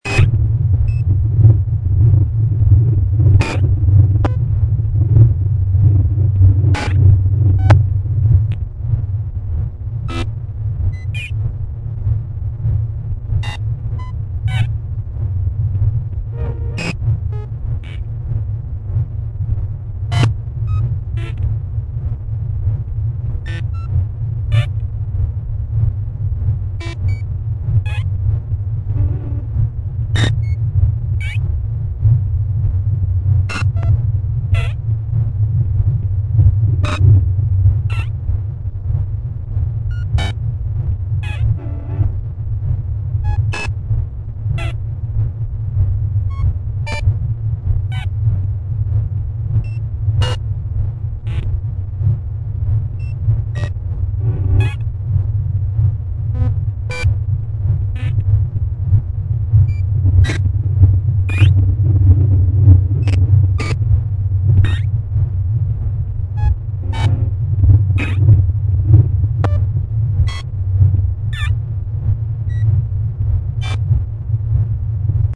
Zelle pulsierend -